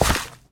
should be correct audio levels.
gravel3.ogg